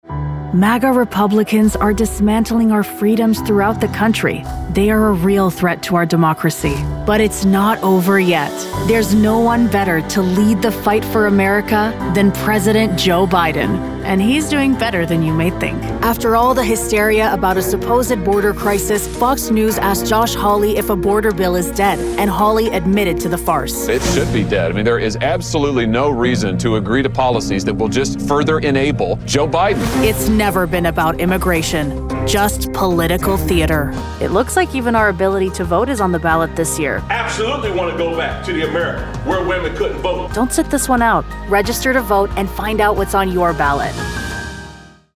Has Own Studio
political